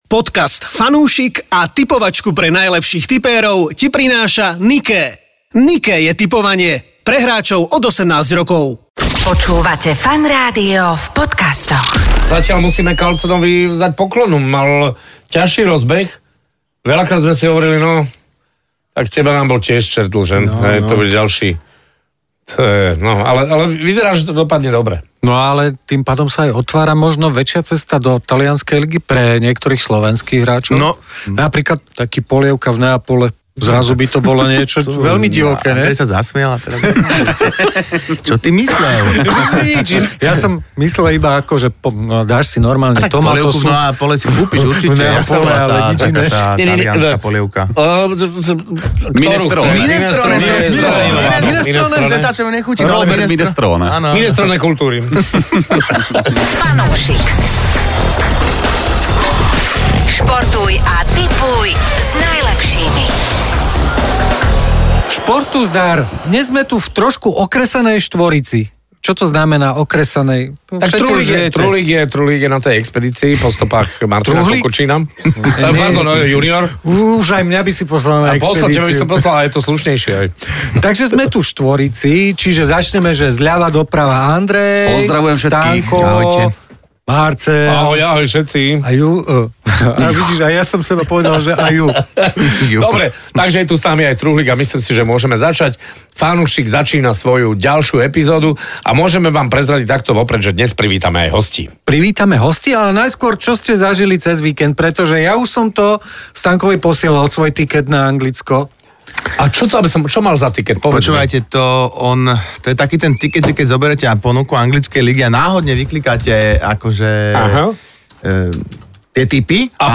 Daj si fanúšikovskú debatku o športe a tipovaní. Toto je podcastová šou od funúšikov pre funúšikov.